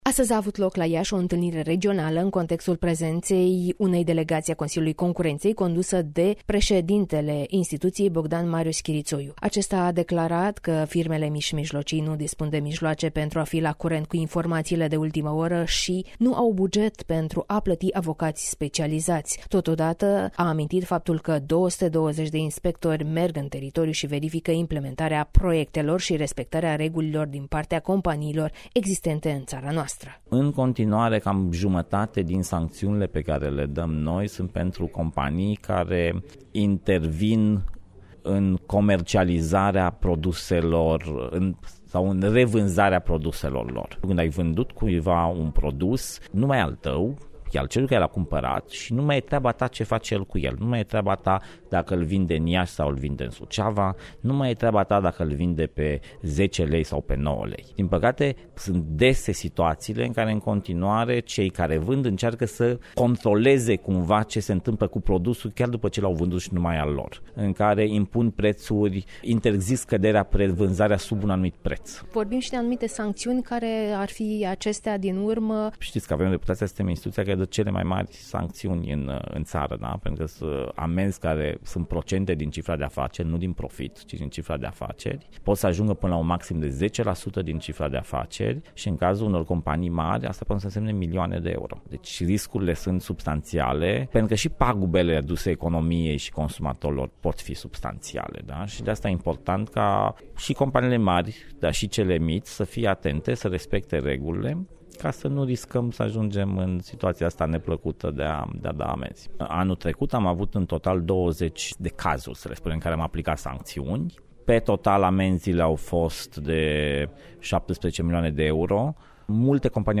Astăzi a avut loc la Iași o întîlnire regională în contextul prezenţei la Iaşi a unei delegaţii a Consiliului Concurenţei, condusă de Bogdan Marius Chiriţoiu, preşedintele instituţiei, în perioada 25 – 26 aprilie 2017.